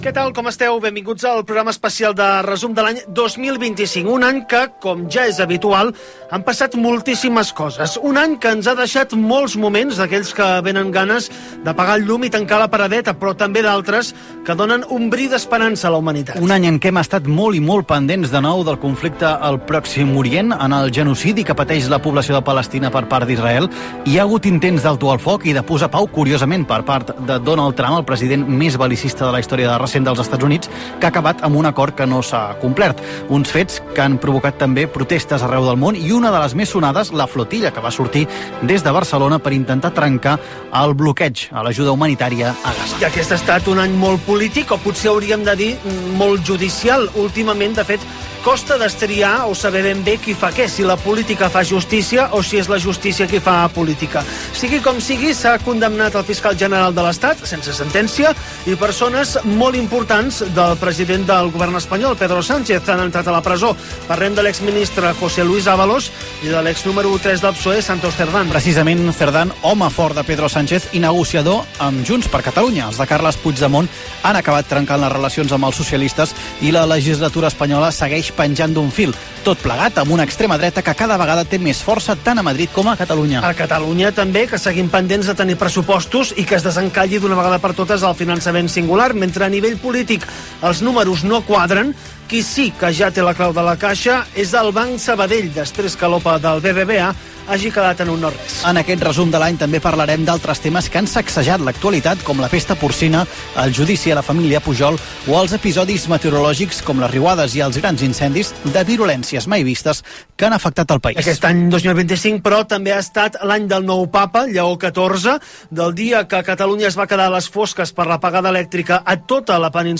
Resum de fragements sonors de notícies de l'any.
Gènere radiofònic Informatiu